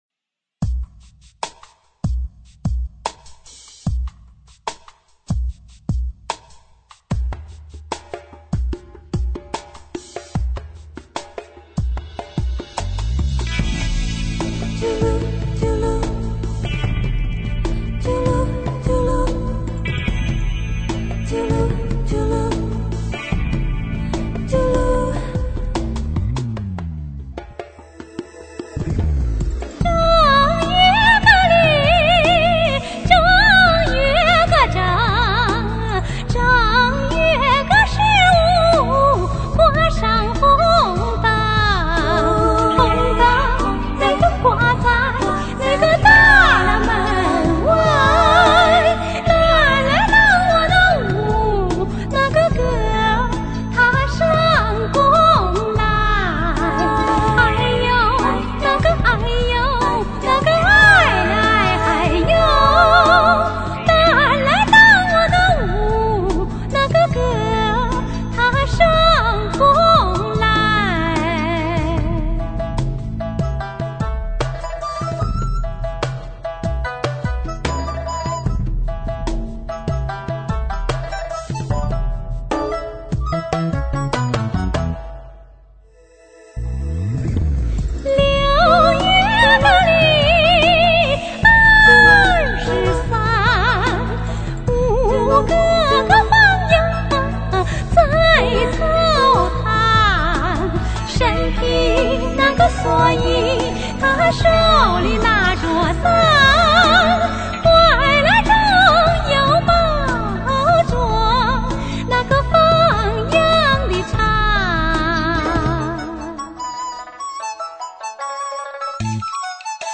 山西(陕西)民歌